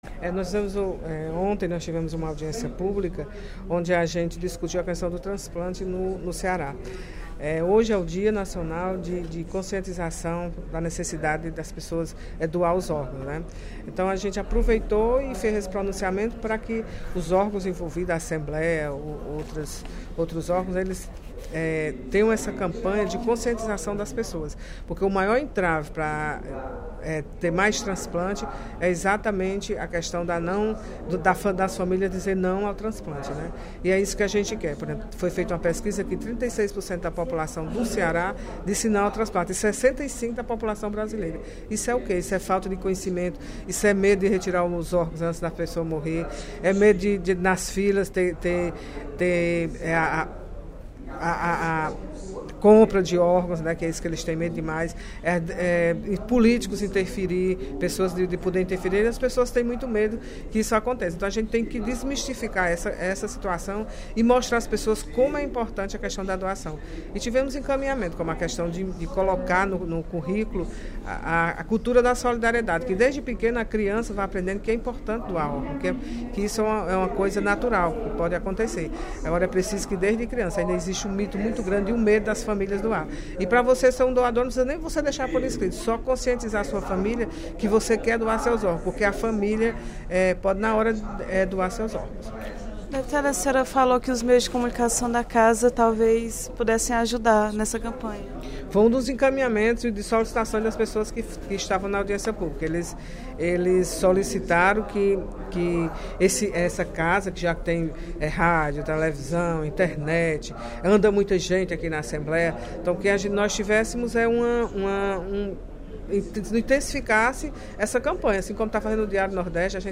A importância da conscientização para a doação de órgãos no Estado foi o tema do pronunciamento da deputada Mirian Sobreira (PSB), durante o primeiro expediente da sessão plenária desta sexta-feira (27/09).